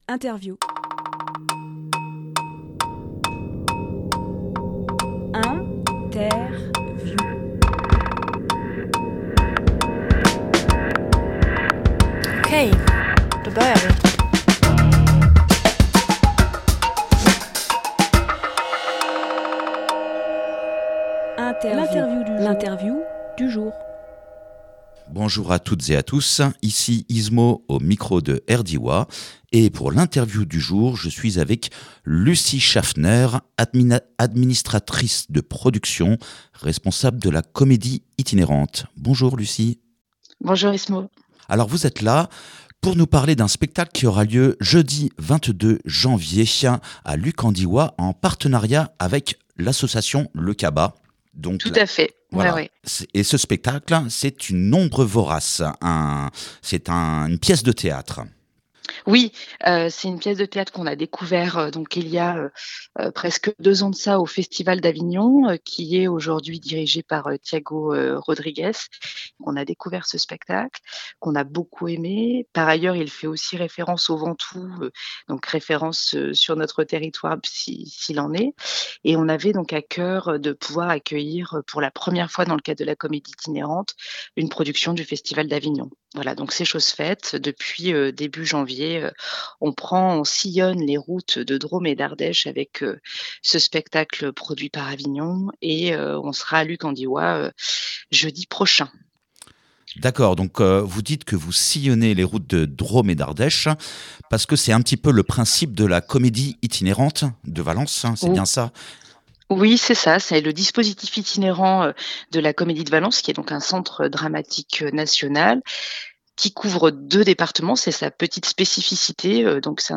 Emission - Interview « Une Ombre Vorace » jeudi 22 janvier à la salle des Voconces à Luc en Diois Publié le 15 janvier 2026 Partager sur…
lieu : Studio RDWA